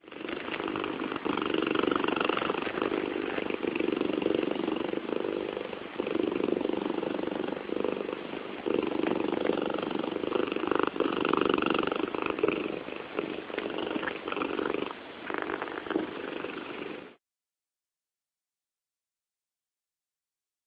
猫的呼噜声
描述：普通猫在我的Zoom H4N附近打呼噜。
Tag: cattus 猫科动物 咕噜咕噜叫